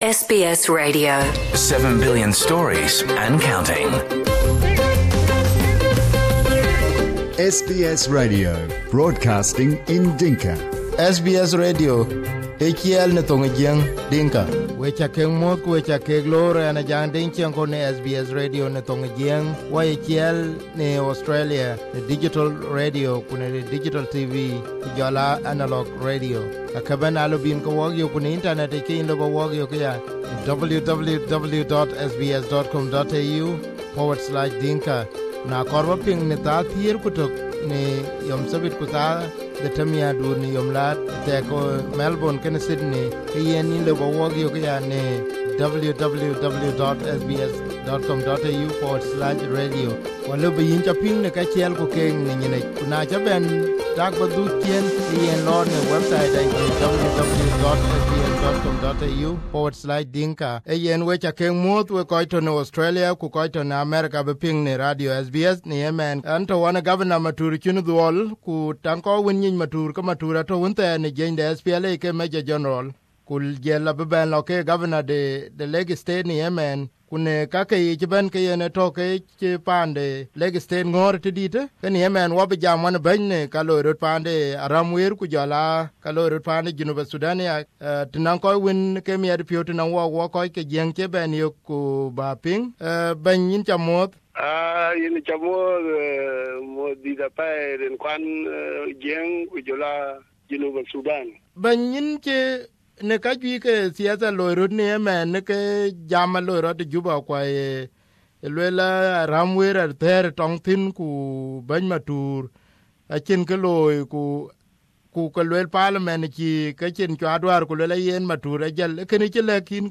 Here is the interview with Matur.